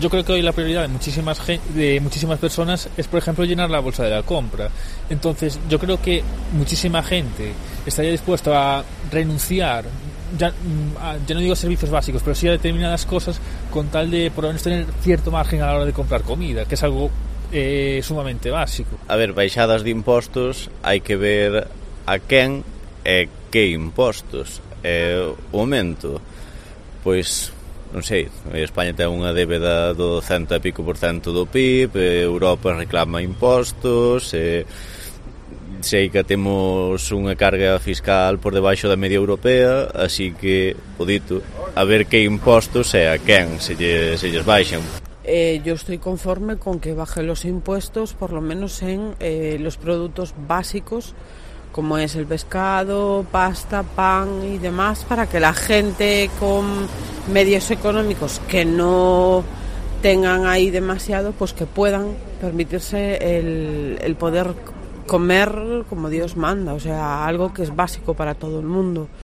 ¿Subir o bajar impuestos en Galicia? preguntamos en la calle